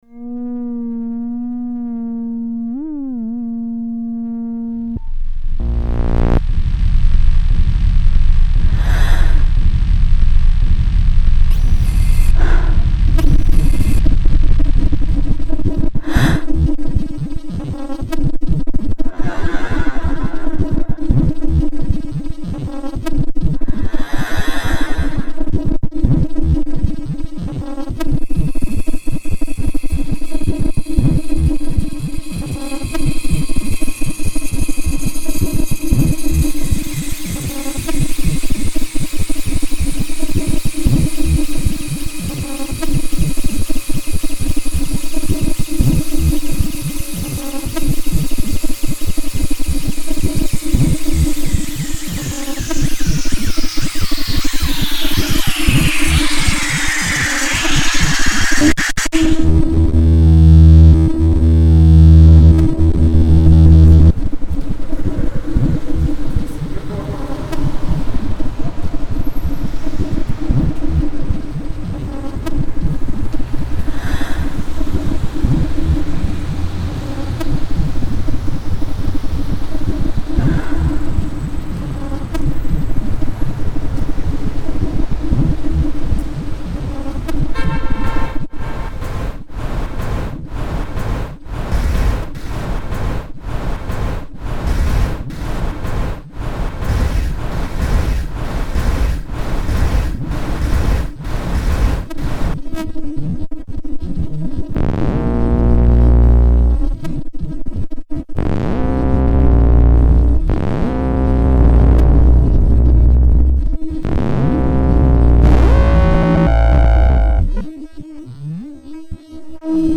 This very digital electroacoustic piece is named after the location of the studio in which it was composed. The recordings for this one are of a pipe that made strange sounds outside the studio, me taking breaths, and a busy downtown street.